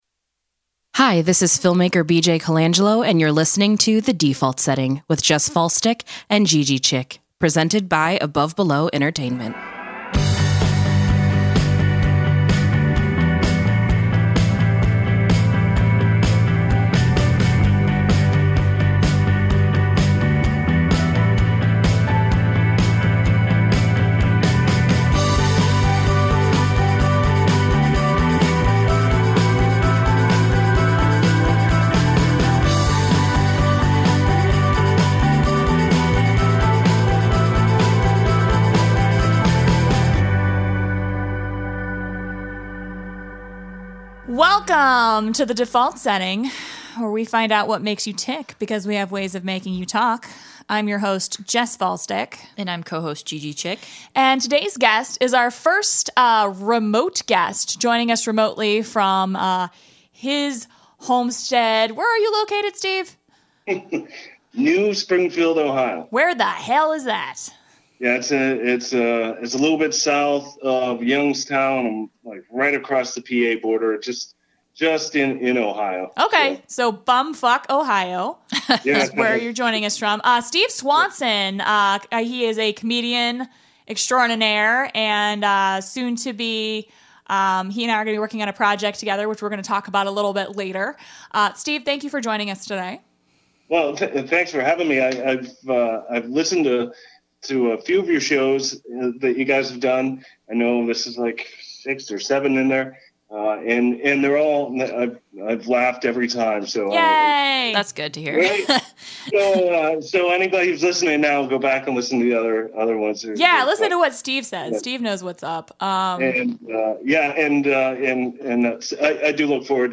Instead we have comic (and first remote Skype guest)